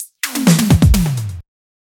Fill 128 BPM (8).wav